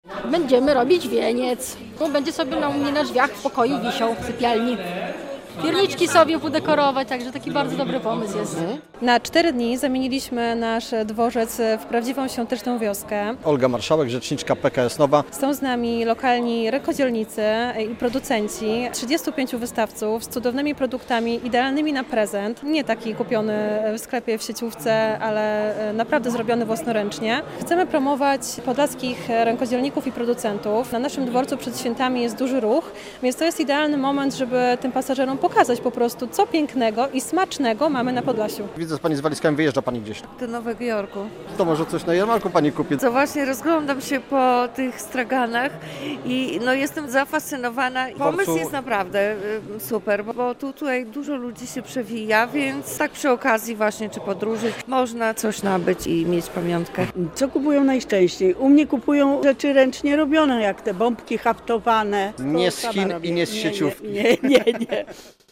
Białostocki dworzec PKS zmienił się w świąteczną wioskę - relacja